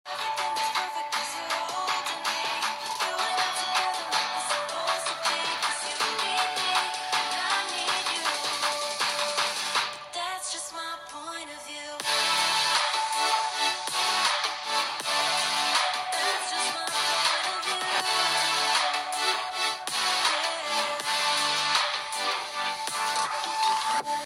肝心の音については、"物足りない"印象です。
▼Google Pixel 7のステレオスピーカーの音はこちら！
一方で、音のクリアさが同価格帯モデルに比べやや物足りない印象でした。
従来モデルよりも音にクリアさがなくなり、雑味を感じます。